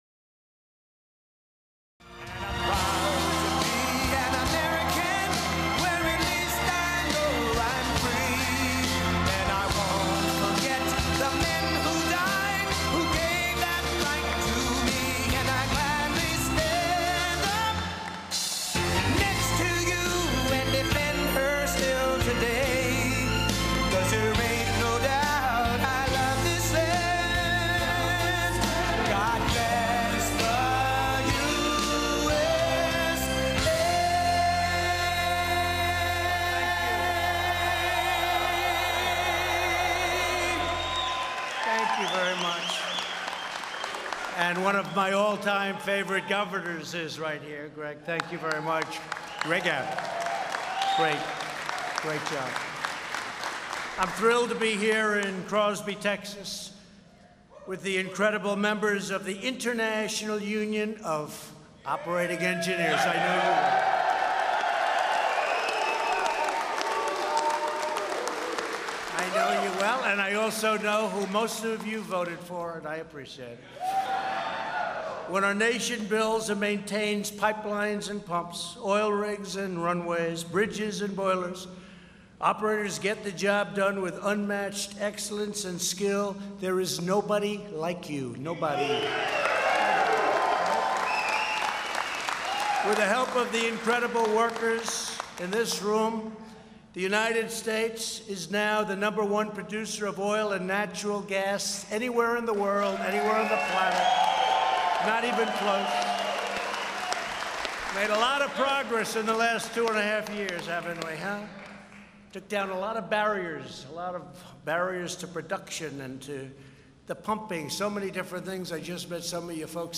U.S. President Donald Trump speaks before signing two executive orders on energy infrastructure development